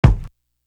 Patagonia Kick.wav